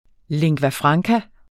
Udtale [ leŋgvaˈfʁɑŋka ]